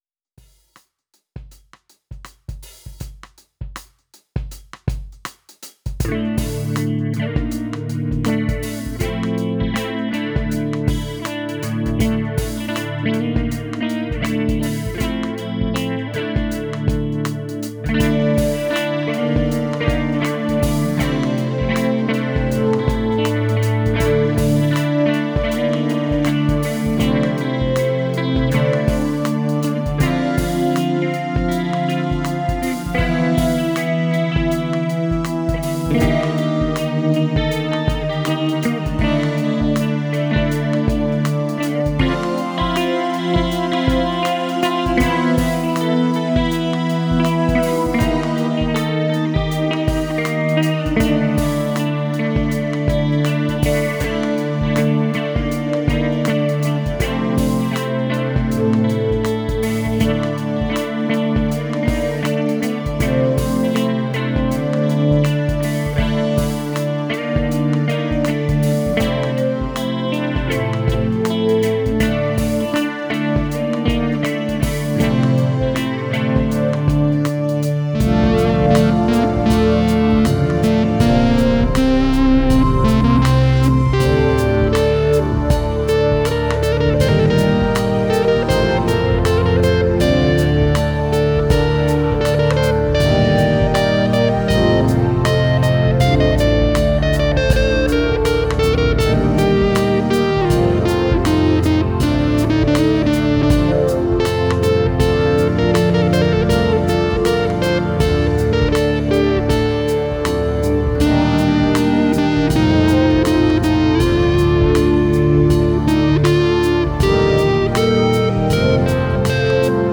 Ein dreiteiliges Stück mit E-Gitarren und Synth-Solo: